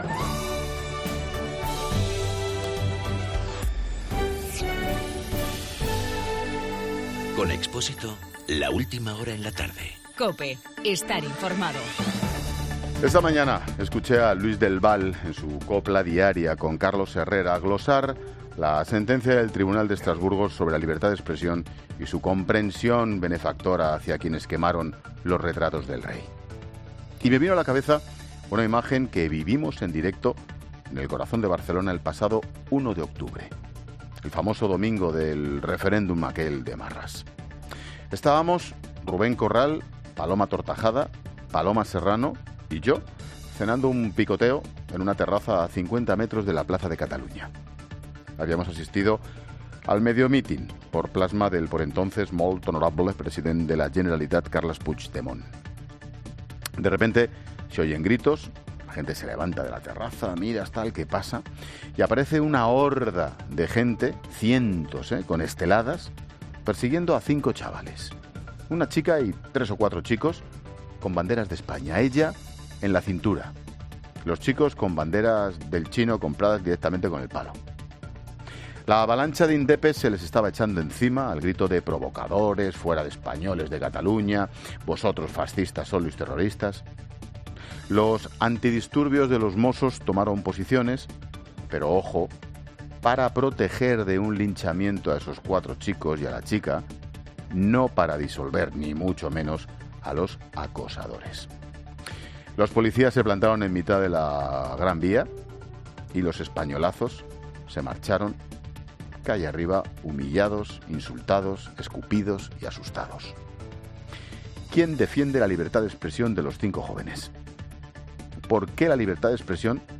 Monólogo de Expósito
El comentario de Ángel Expósito sobre la sentencia de Estrasburgo sobre quema de una foto de los Reyes.